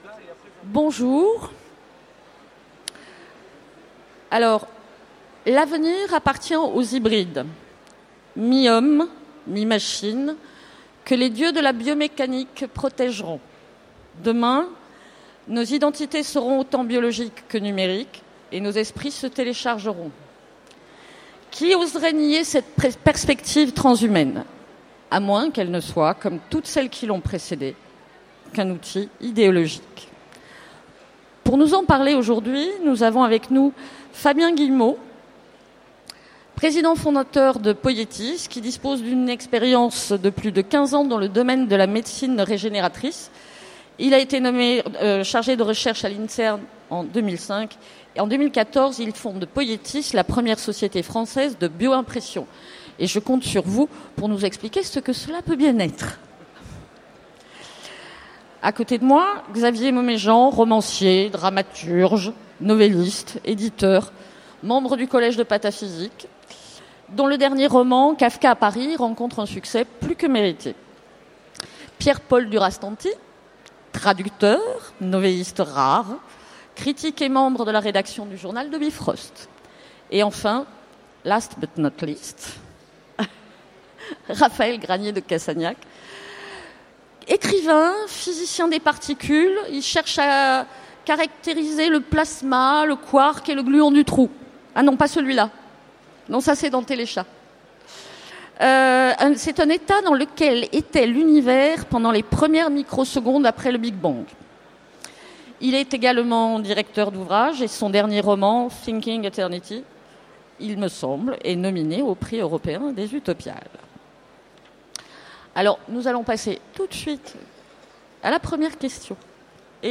Utopiales 2015 : Conférence Le règne de l’Homme-Machine aura-t-il lieu ?